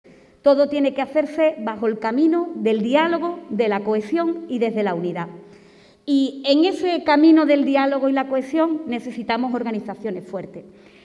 La presidenta de la Diputación, Irene García, ha intervenido en la apertura del decimocuarto Congreso Provincial de UGT en Cádiz, celebrado el Campus de Excelencia Turística El Madrugador en El Puerto de Santa María, con el fin de elegir a la nueva ejecutiva de este sindicato.